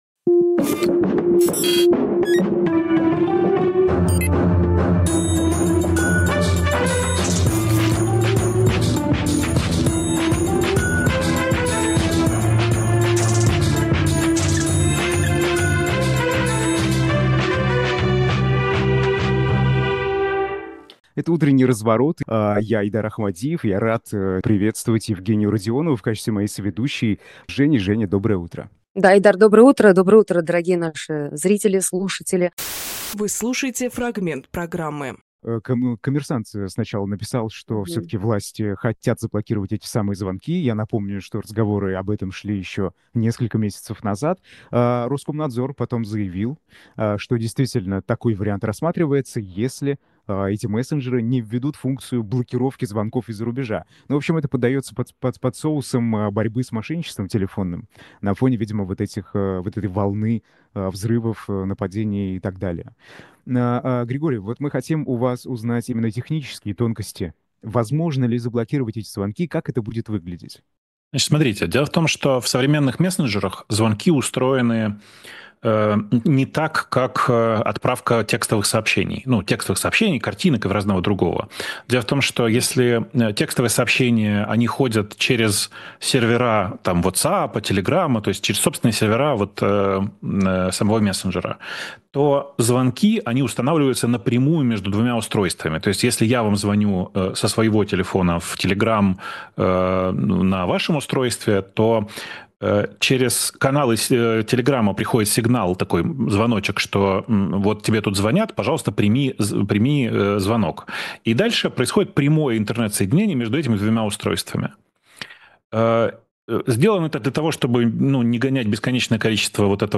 Фрагмент эфира от 25 декабря.